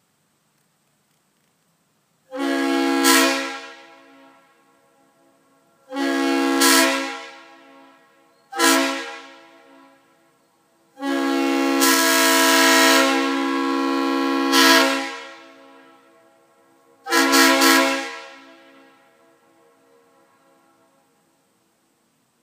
For me growing up with Conrail, this horn screams "freight train."
The 'RS' power chambers give this horn a distictive squeal when starting to blow.